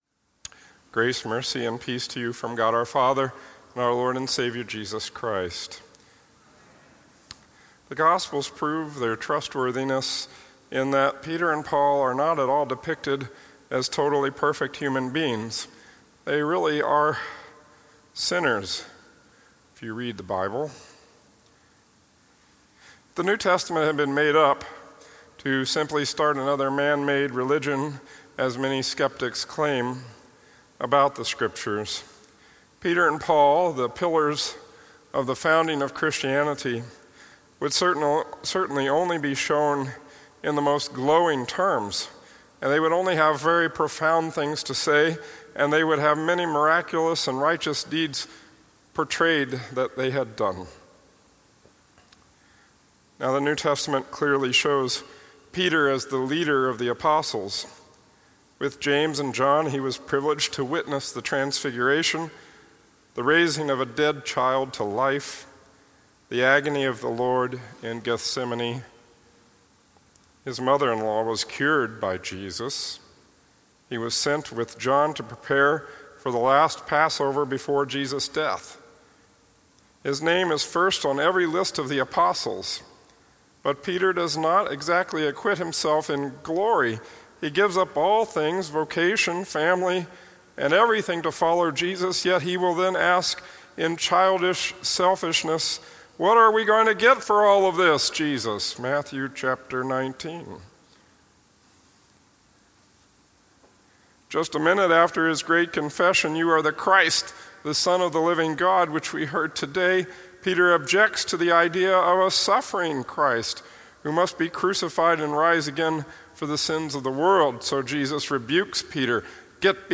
Feast of Sts. Peter and Paul, Apostles and Martyrs (2018)